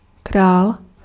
Ääntäminen
Synonyymit sovrano sire Ääntäminen Tuntematon aksentti: IPA: /ˈre/ IPA: /rɛ/ Haettu sana löytyi näillä lähdekielillä: italia Käännös Ääninäyte 1. král {m} Suku: m . Taivutusmuodot Monikko re Luokat Heteronyymit Indoeurooppalaisista kantakielistä johdetut sanat Latinasta johdetut sanat Monikolliset sanat Shakki